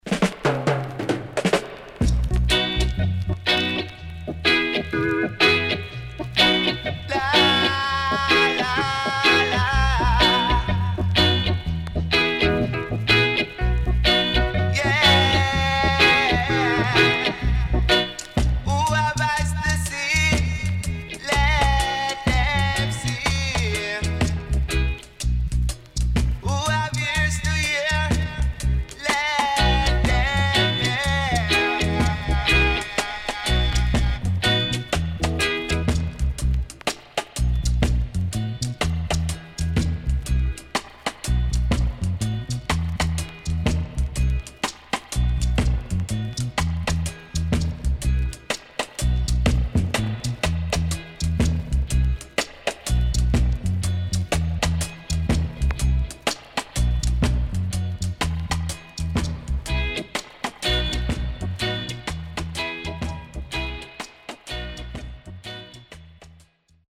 HOME > Back Order [VINTAGE 7inch]  >  70’s DEEJAY
CONDITION SIDE A:VG(OK)〜VG+
SIDE A:出だし所々チリノイズ入ります。